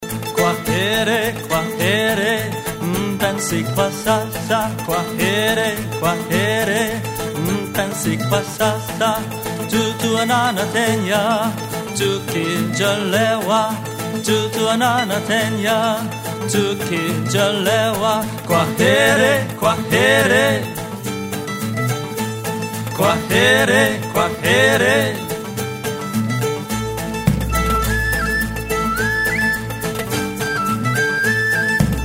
Folk Song Lyrics